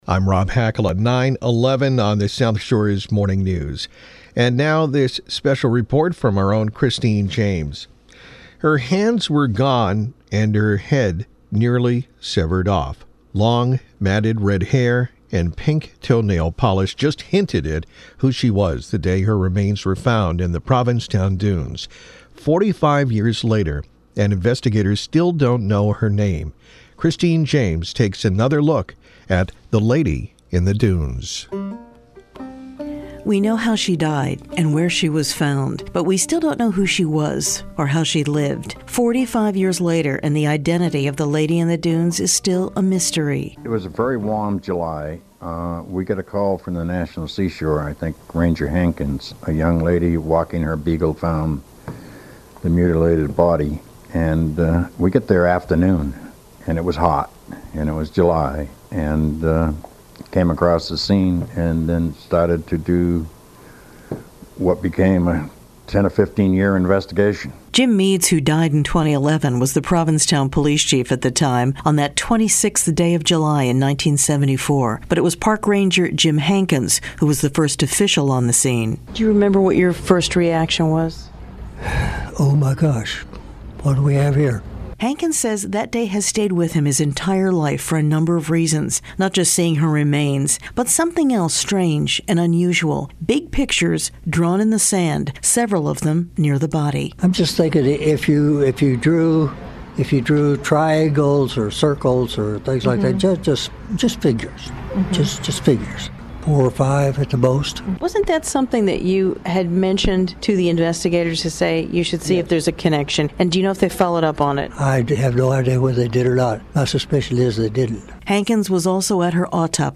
‘Lady in the Dunes’ Cold Case Special Report